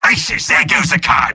Robot-filtered lines from MvM. This is an audio clip from the game Team Fortress 2 .